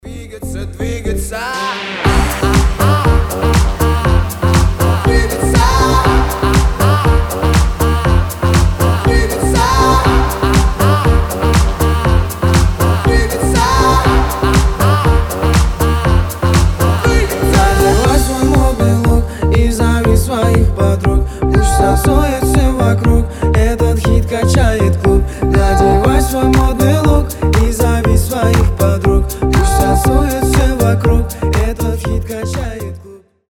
заводные
басы
динамичные